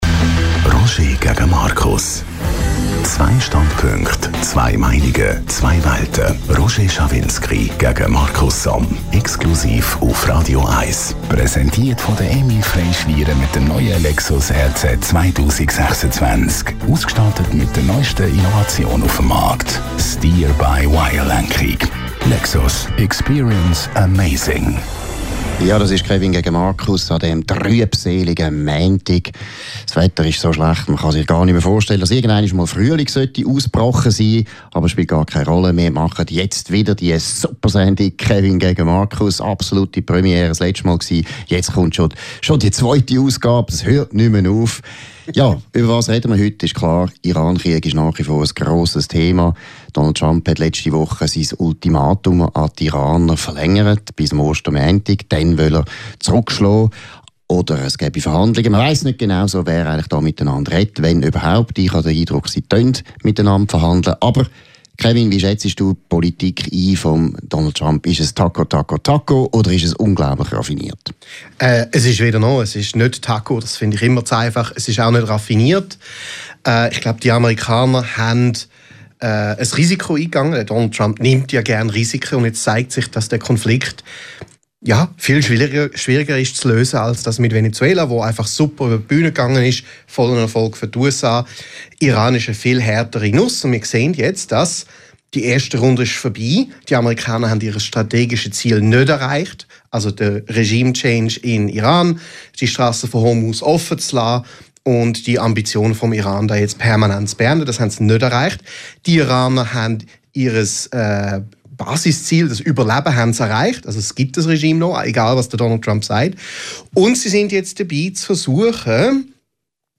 Download - Roger gegen Markus - Live On Tour | Podbean
Verleger und Chefredaktor der Basler Zeitung Markus Somm und Radio 1-Chef Roger Schawinski diskutieren in kontroverser Form über aktuelle Themen der Woche.